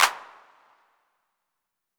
Index of /m8-backup/M8/Samples/Drums/Hits/TR808/CP